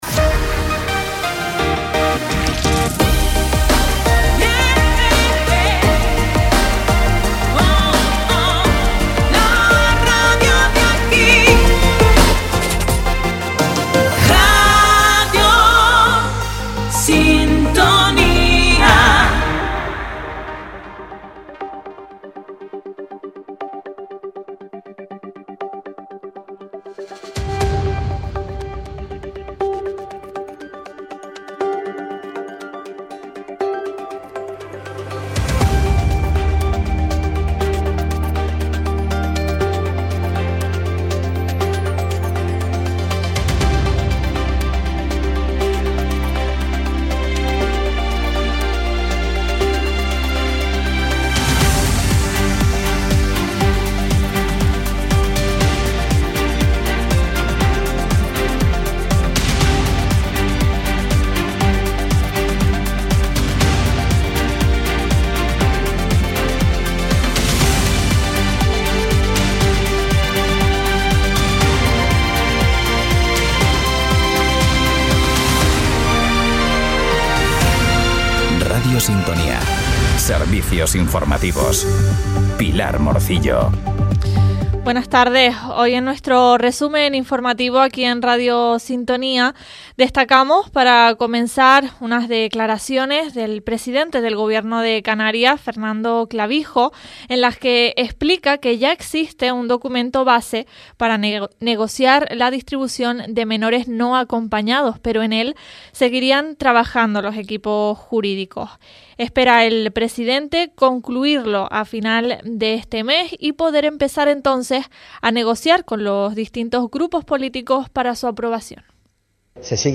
Informativos en Radio Sintonía - 27.01.25